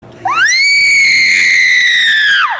PLAY high pitched scream sound effect
high-pitched-scream.mp3